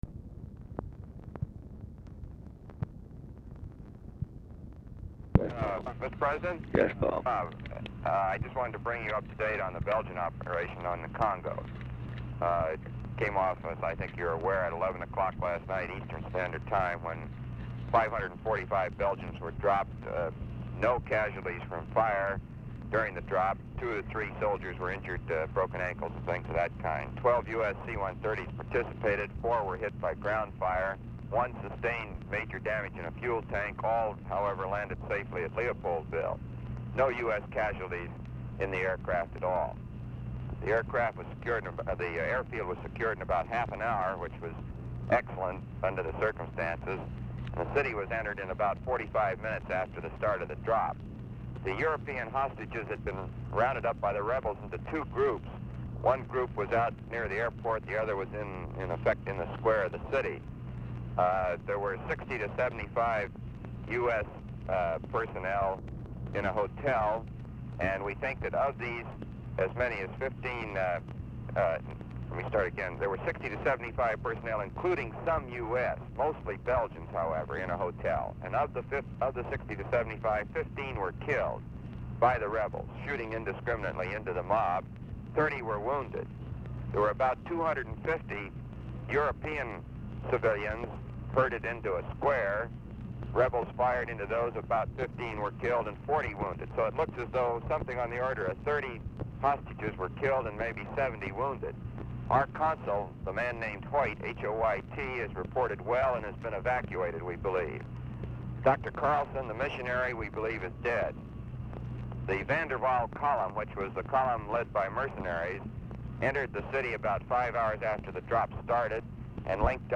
Telephone conversation # 6470, sound recording, LBJ and ROBERT MCNAMARA, 11/24/1964, 7:20AM | Discover LBJ
Format Dictation belt
Location Of Speaker 1 LBJ Ranch, near Stonewall, Texas
Specific Item Type Telephone conversation